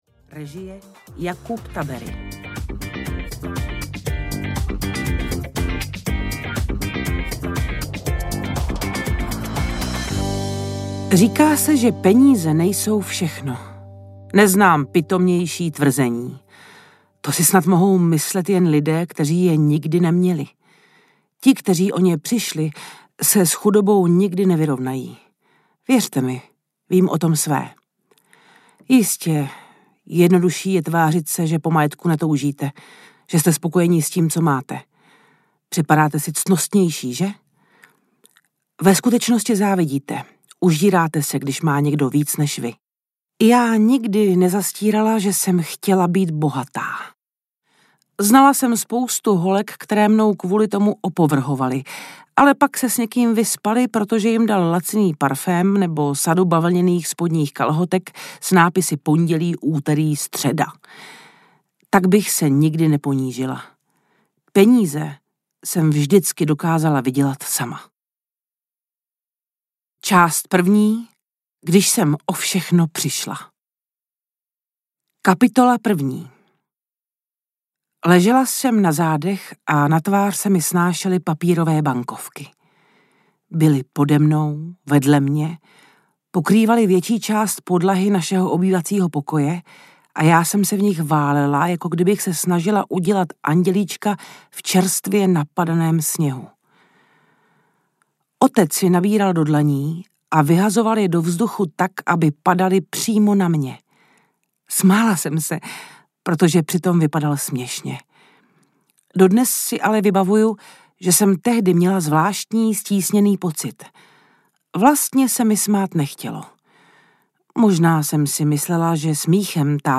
Vekslačka audiokniha
Ukázka z knihy
• InterpretLenka Krobotová